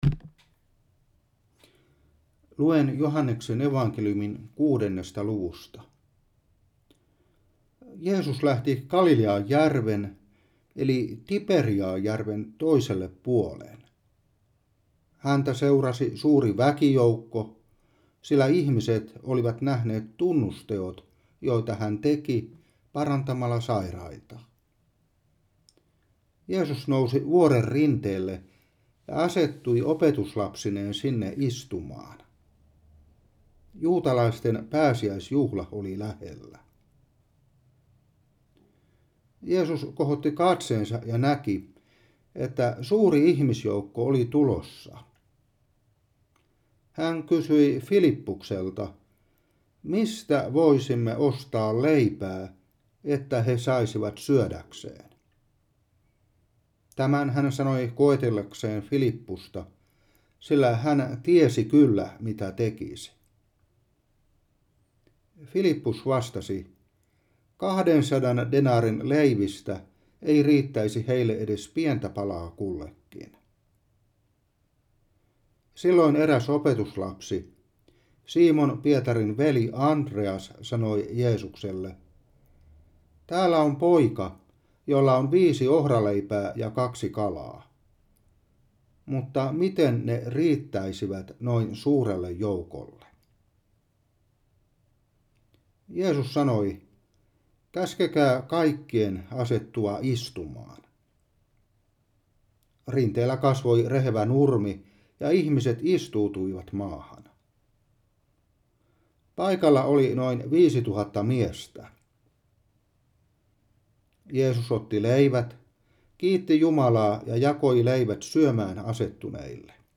Saarna 2025-3.